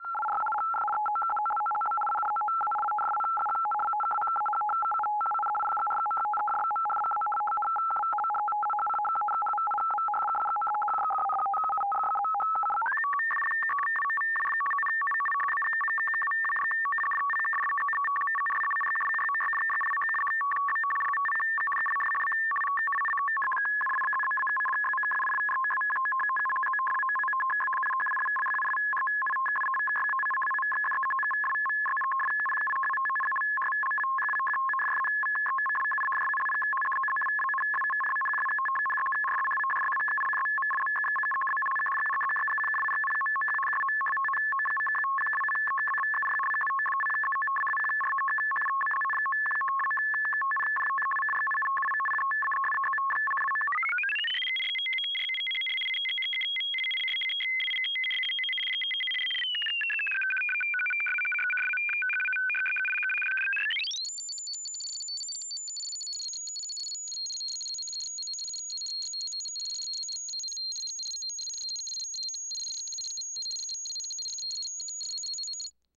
Morse Code: Synthesized, Multiple Speeds And Effects. Mono